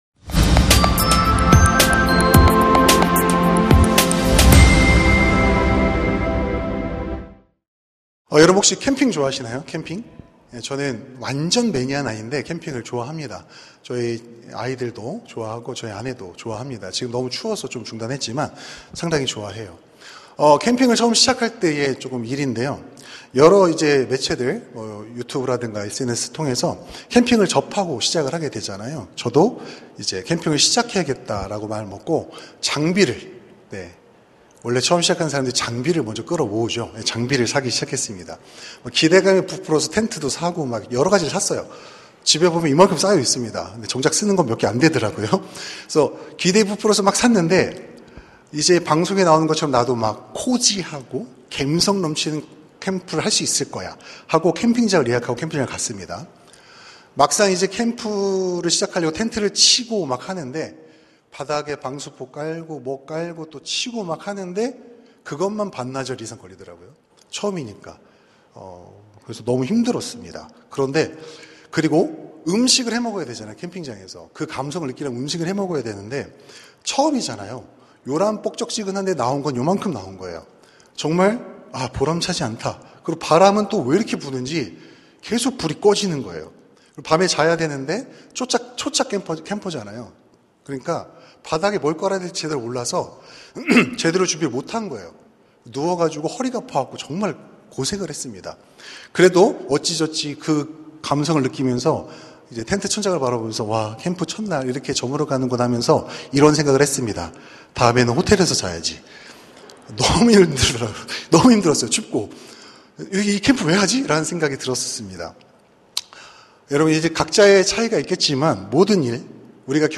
설교 : 파워웬즈데이 약속이랑 다르잖아요...? 설교본문 : 시편 5:7 설교자